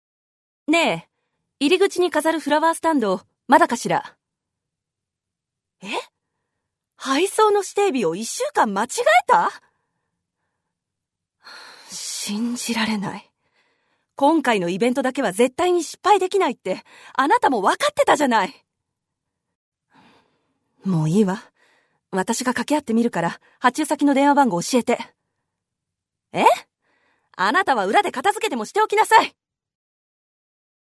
Voice Sample
セリフ1